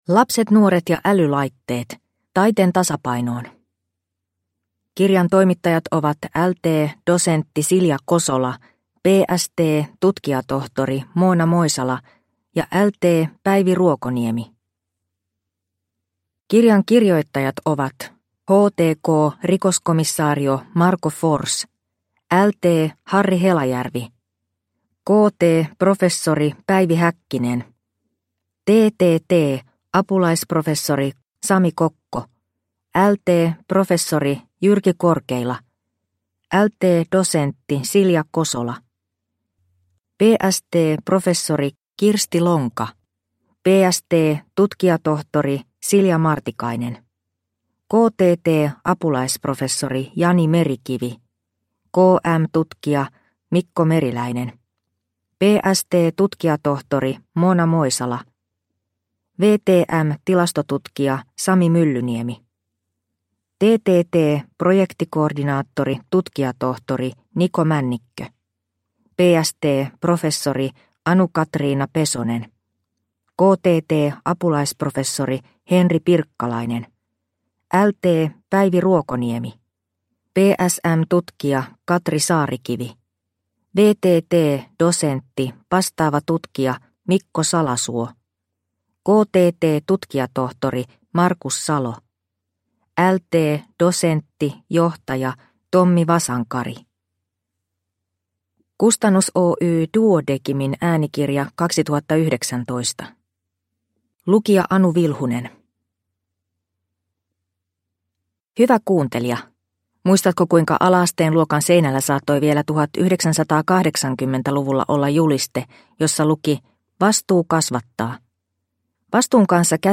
Lapset, nuoret ja älylaitteet – Ljudbok – Laddas ner